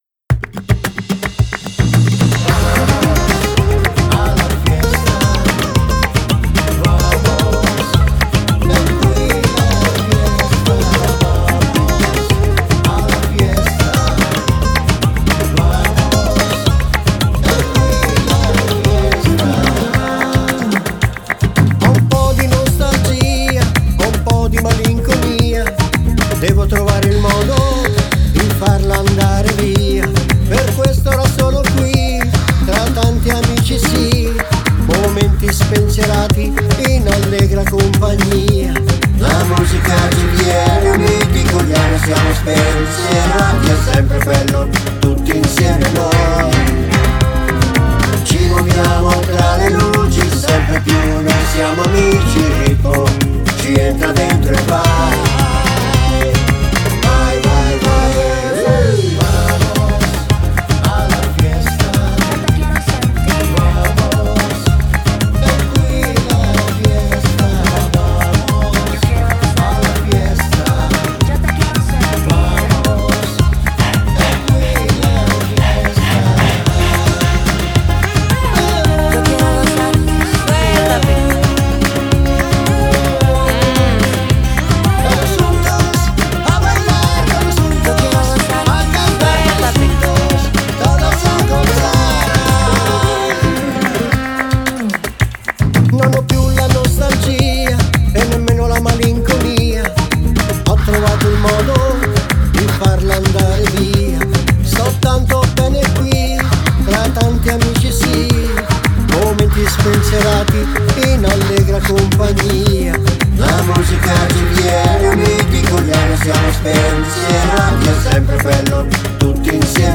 Flamenco
Canzone - Flamento.